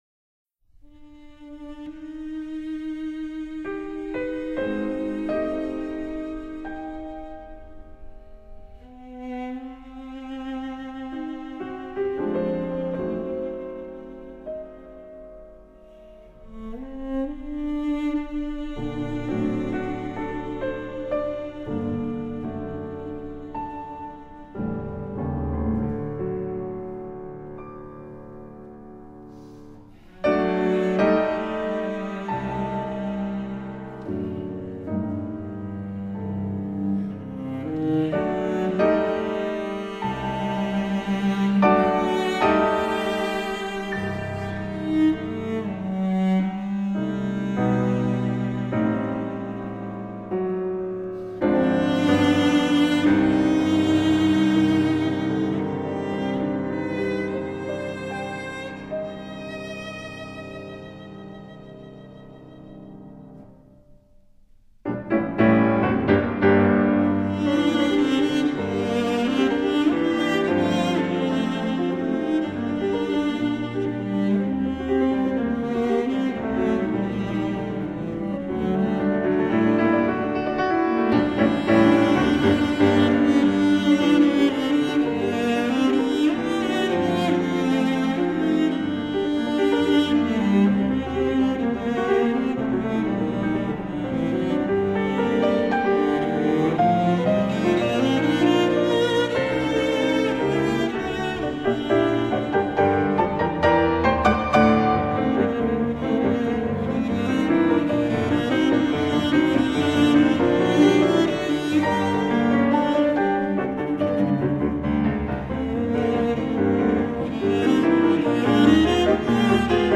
Cello Sonata In G Minor